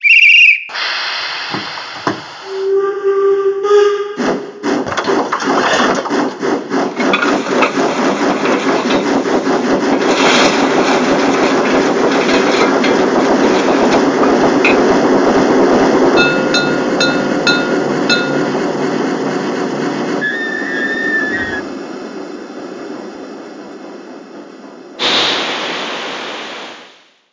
Märklin 37086 Tenderdampflokomotive BR 86.0-8 DB, AC 3L, digital mfx+/MM/DCC/Sound - H0
Märklin 37086 Demo-Sound.mp3